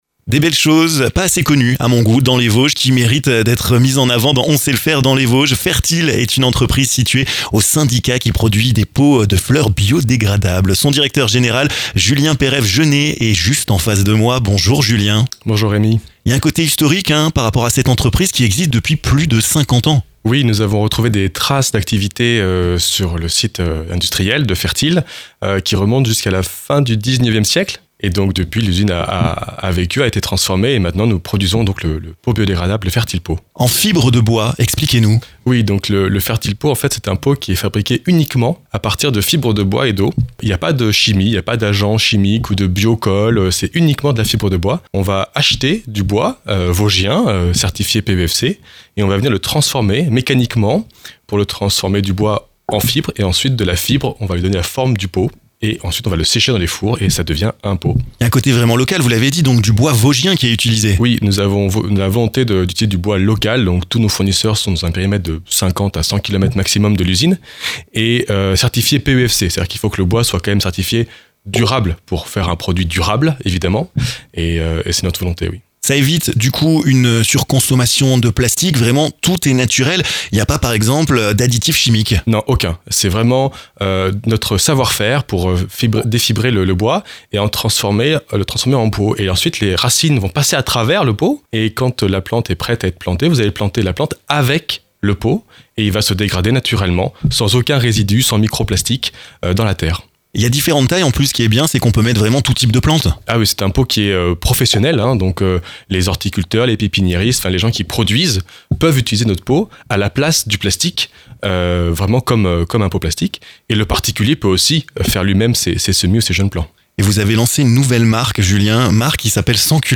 vous explique son savoir-faire dans cette interview!